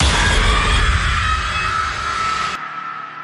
jumpscarefinal2.mp3